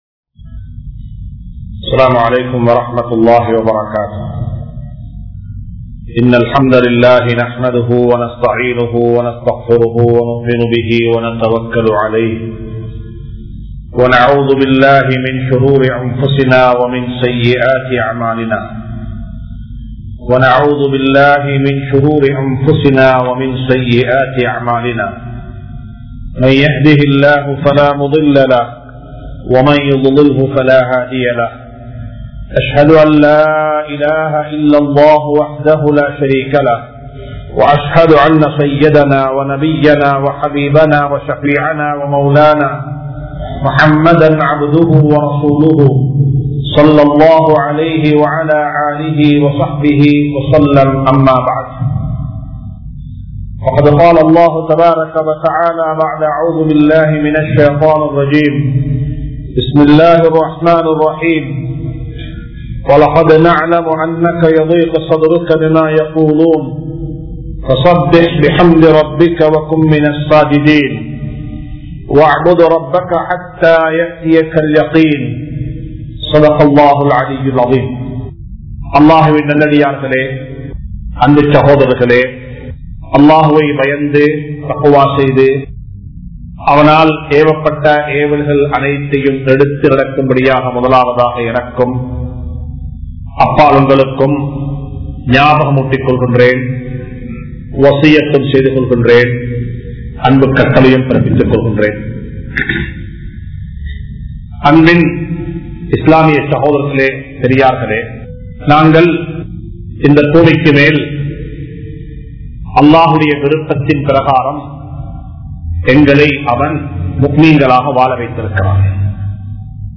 (மறைவானவற்றை நம்புங்கள்) | Audio Bayans | All Ceylon Muslim Youth Community | Addalaichenai
Thaqwa Jumua Masjith